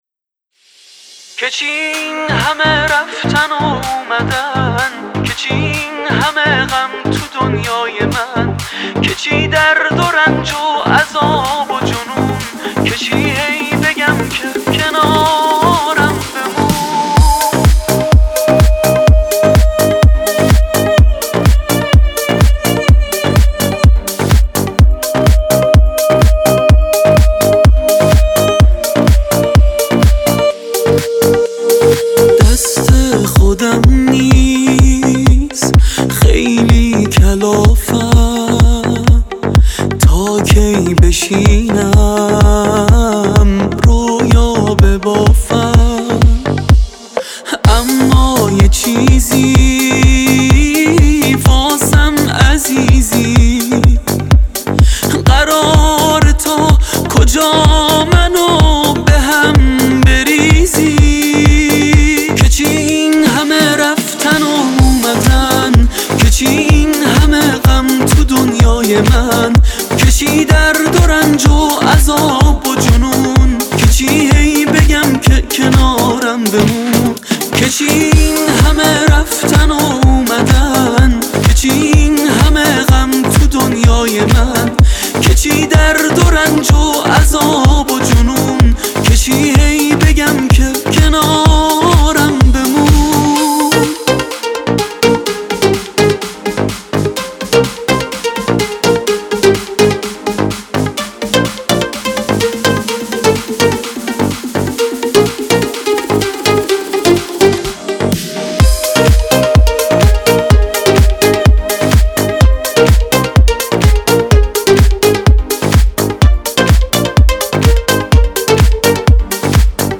دیس لاو